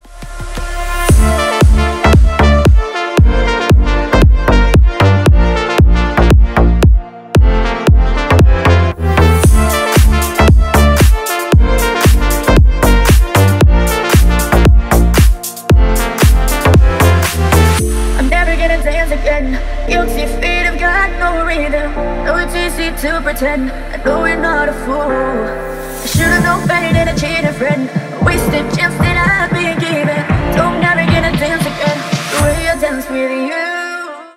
Танцевальные
клубные # кавер